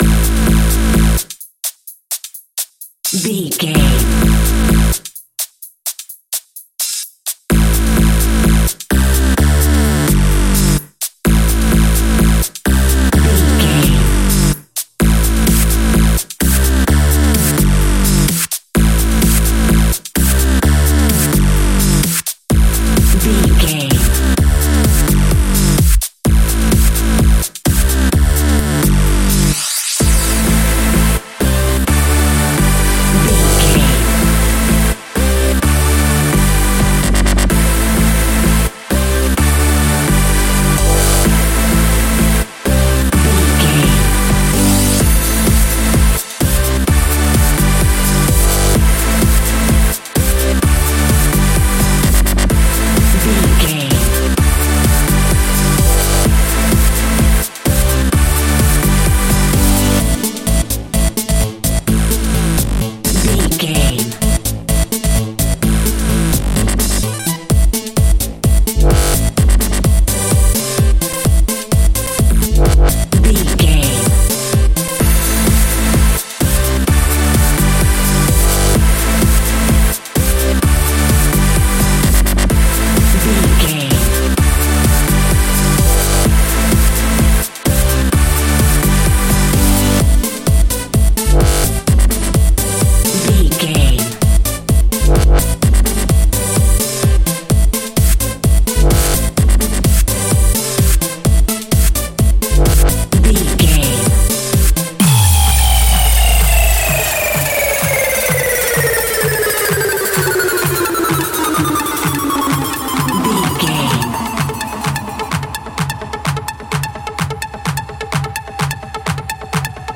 Dubstep Electro Glitch.
Aeolian/Minor
Fast
aggressive
dark
groovy
driving
energetic
synthesiser
drum machine
breakbeat
synth leads
synth bass